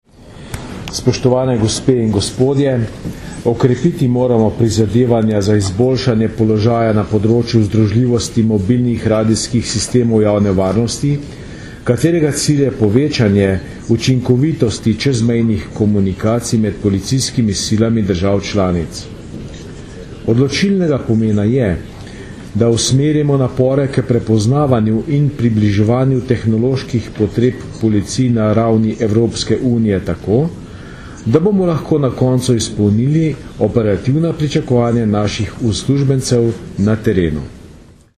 Zvočni posnetek g. Jožeta Romška, generalnega direktorja policije (mp3)